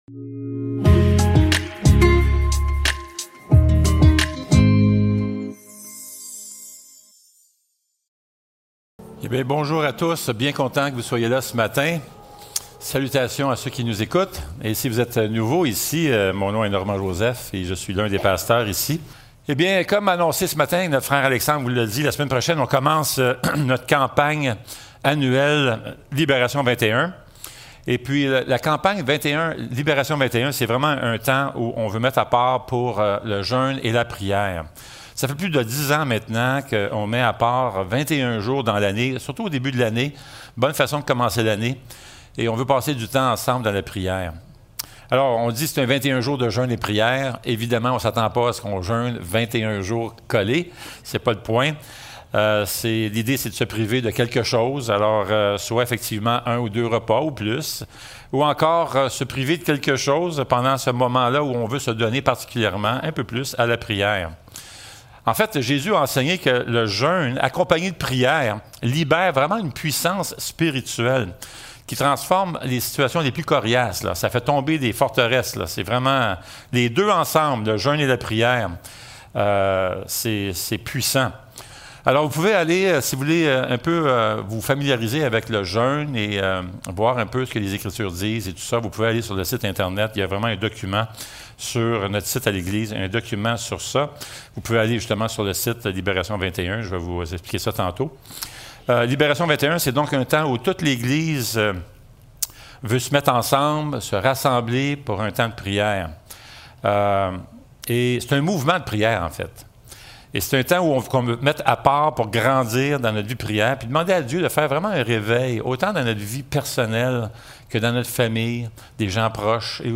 Matthieu 6.9-13 Service Type: Célébration dimanche matin Description